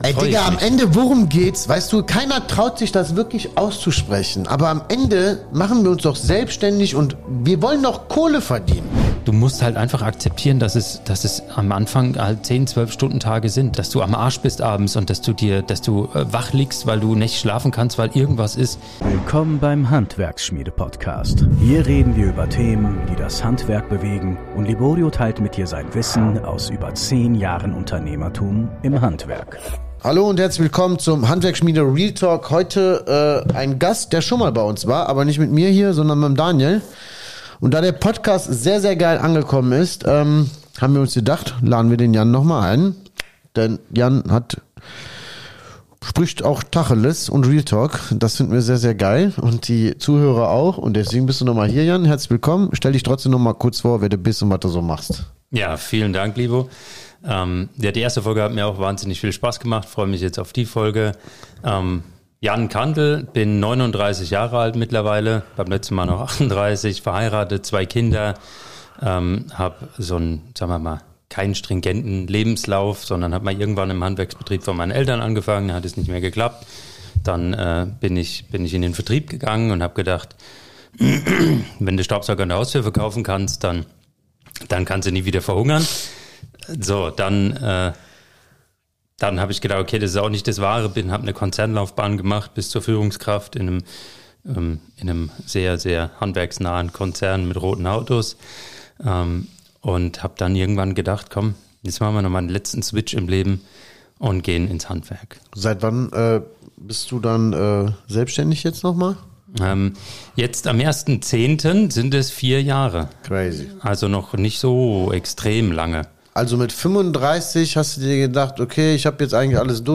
Vom Großkonzern zurück ins Handwerk | Interview